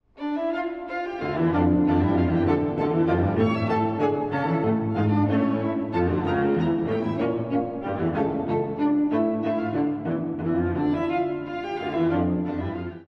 第3楽章 カノンが生む異様なメヌエット
全体がカノン形式で進みます。
どこか永遠に自問自答しているよう。
光の差す中間部があるものの、また暗い思考へと戻っていきます。